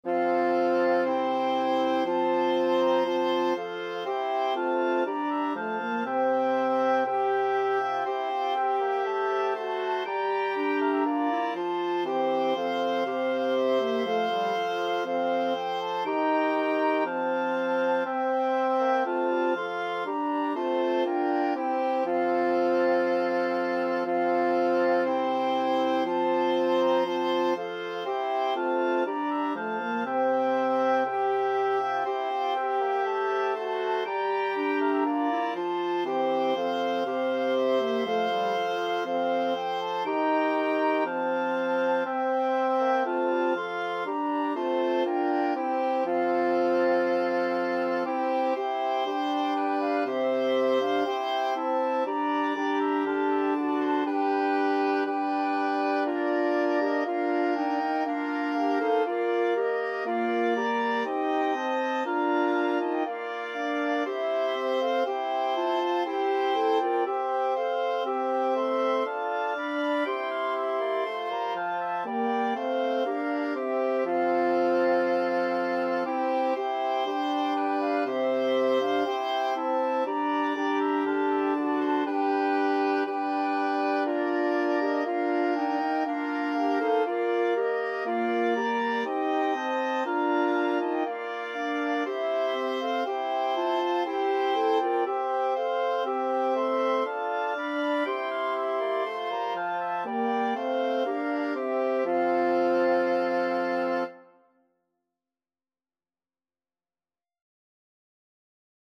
FluteOboeClarinetFrench HornBassoon
2/2 (View more 2/2 Music)
Classical (View more Classical Wind Quintet Music)